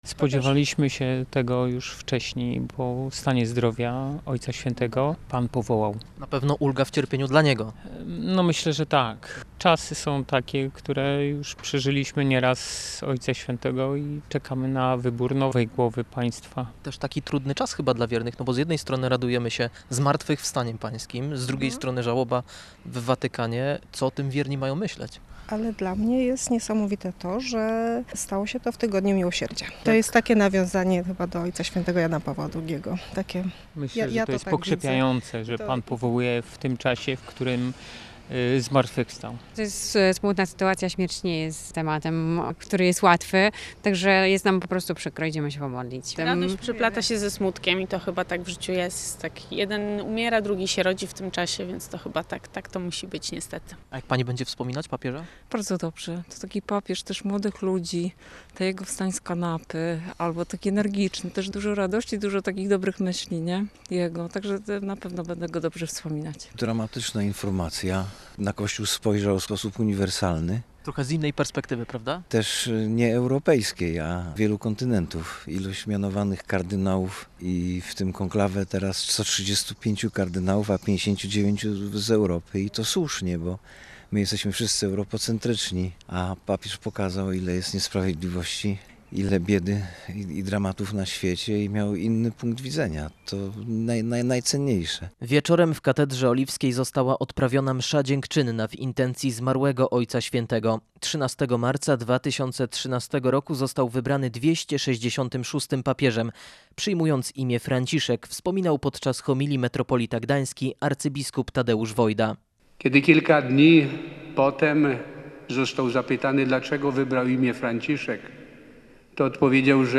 Wieczorem w archikatedrze oliwskiej w Gdańsku została odprawiona msza dziękczynna w intencji zmarłego Ojca Świętego.